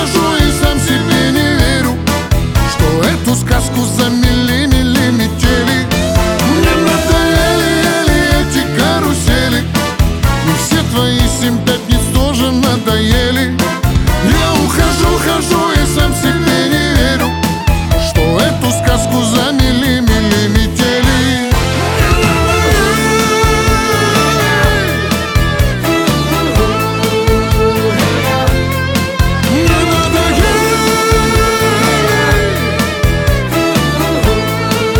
Chanson in Russian
Жанр: Русский поп / Русский рэп / Русский шансон / Русские